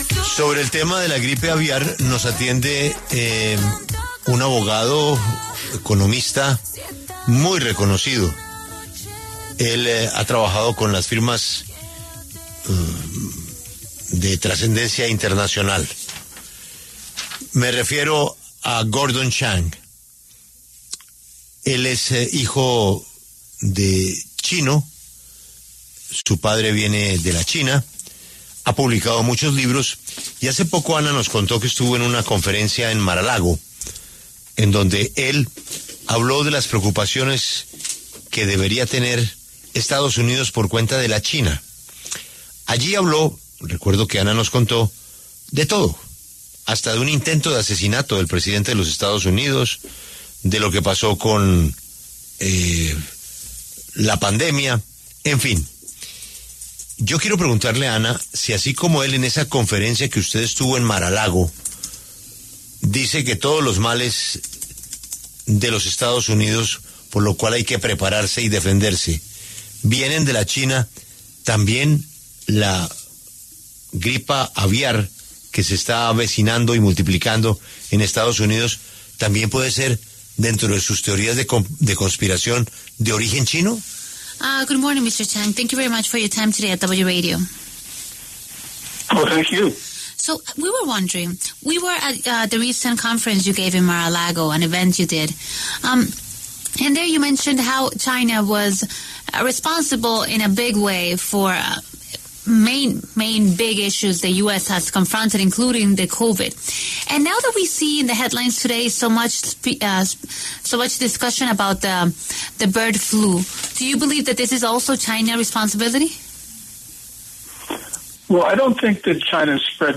Gordon Chang, abogado y comentarista político estadounidense, se refirió en La W a las tensiones comerciales entre Estados Unidos y China, y su impacto en el comercio global.